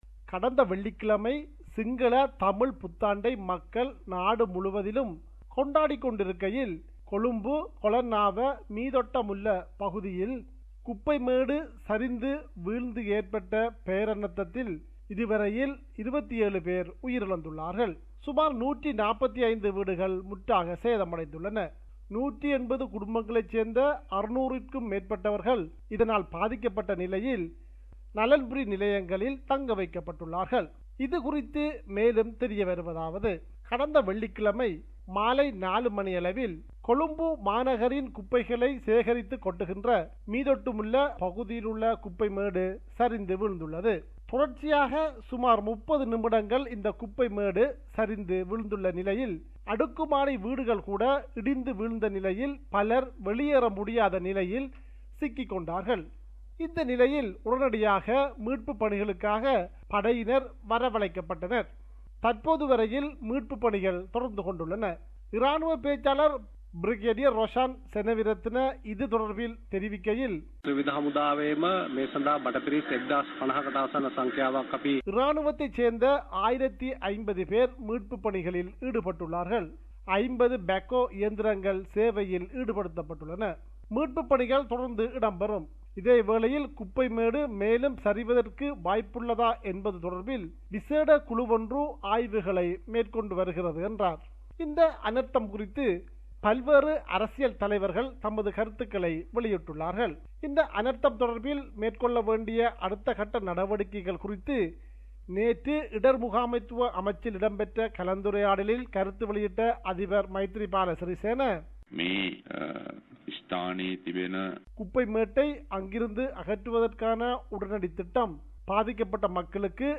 our correspondent in Sri Lanka, compiled a report about the incident.